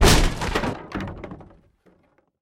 Звуки выбитой двери
Звук выбитой ногой двери